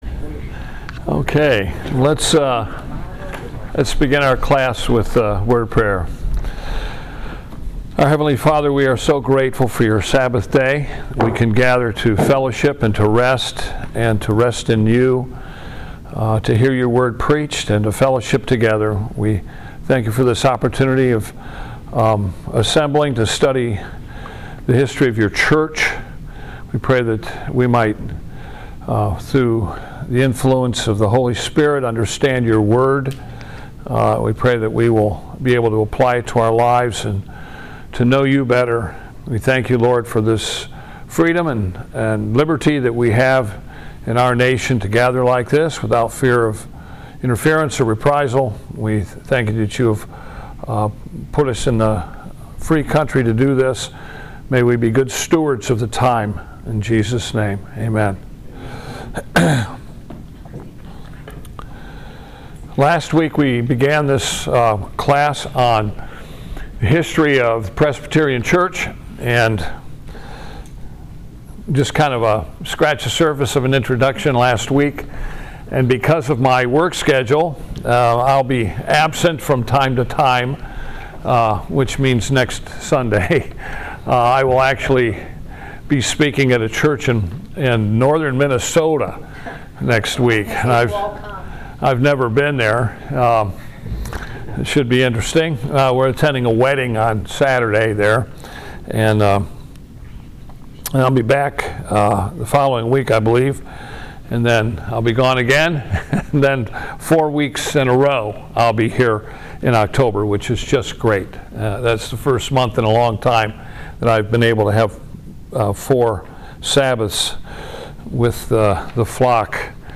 Presbyterian History – Lecture 2
Presbyterian History Service Type: Sabbath School « The Work of False Teachers to Undermine the Gospel Ministry
What-is-Presbyterianism-Lecture-2.mp3